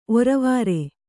♪ oravāre